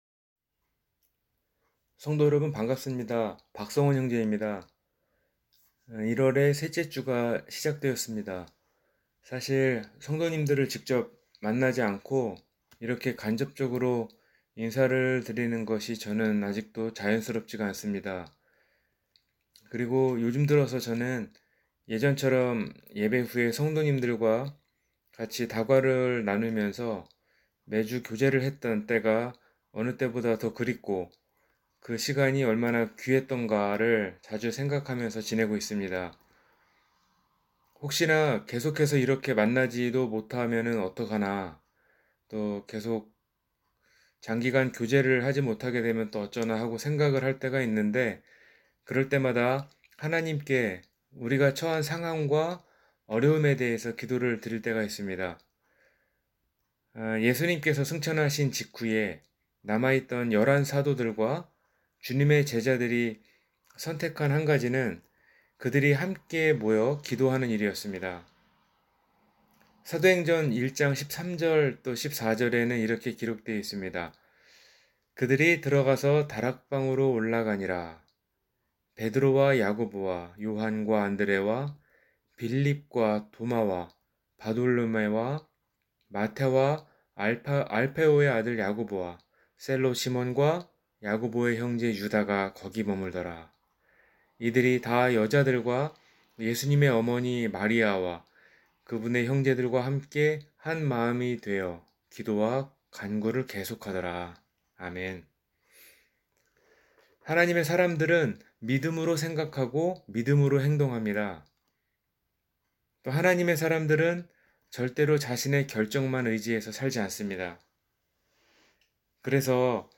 영원한 생명을 붙들라 – 주일설교